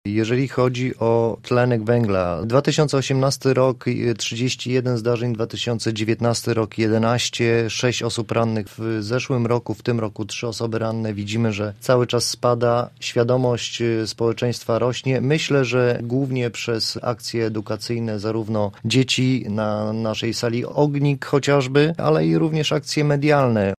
W tym roku odnotowaliśmy ponad 10 zdarzeń związanych z czadem – mówił w Rozmowie Punkt 9